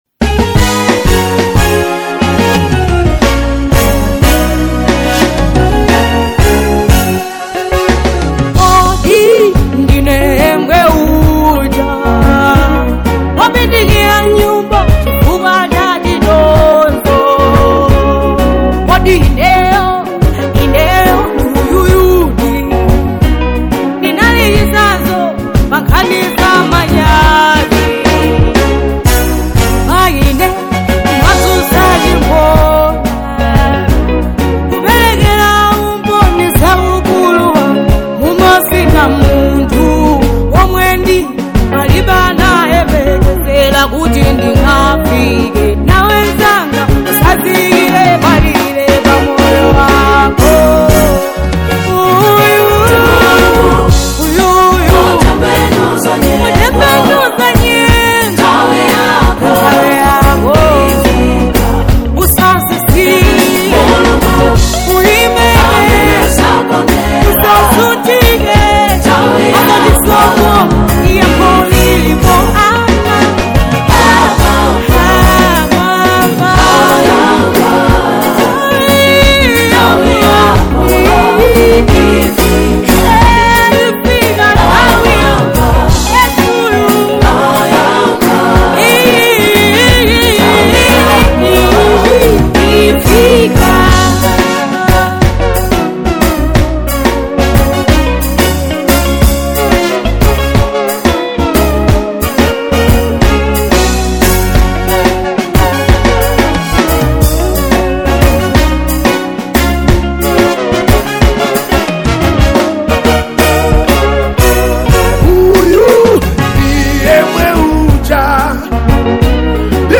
A soulful Malawi gospel track in high quality.